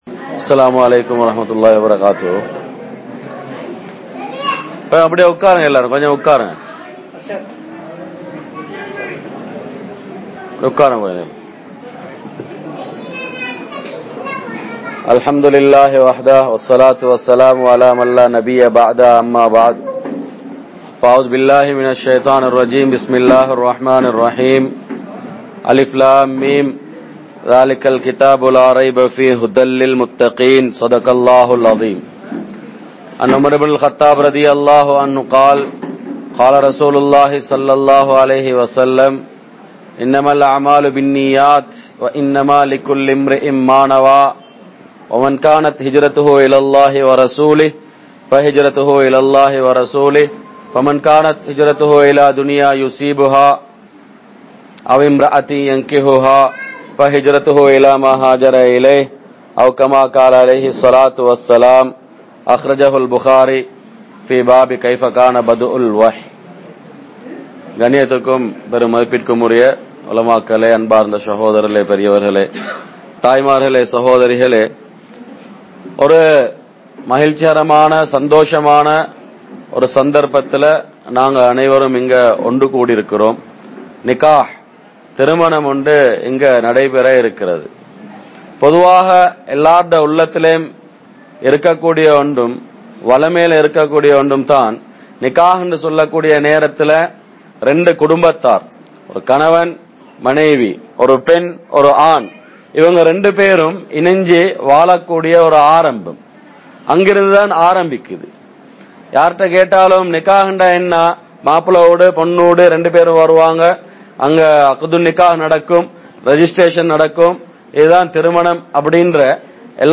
Anniya Aangalaium Pengalaium Paarkum Indraya Kanavan Manaivi (அன்னிய ஆண்களையும் பெண்களையும் பார்க்கும் இன்றைய கனவன் மனைவி) | Audio Bayans | All Ceylon Muslim Youth Community | Addalaichenai
Yasir Arafath Jumua Masjidh